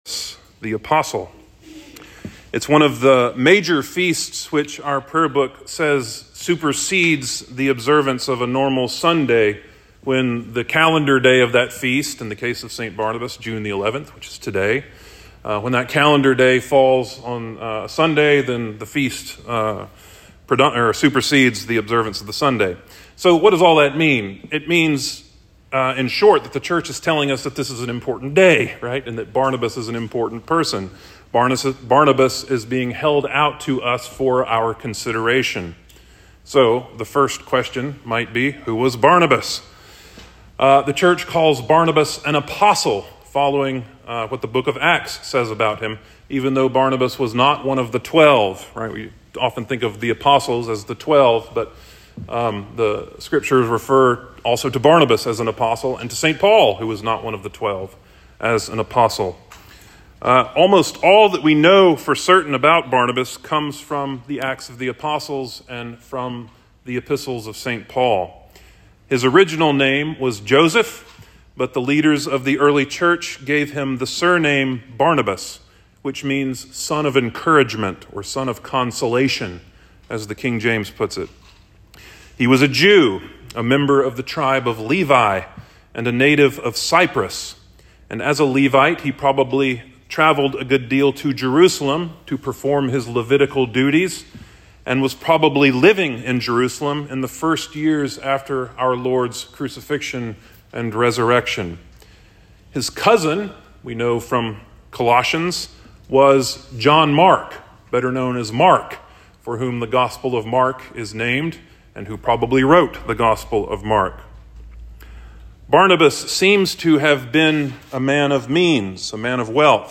St. Barnabas Sermon 06.11.23
Sermon-for-St.-Barnabas-Day-2023.mp3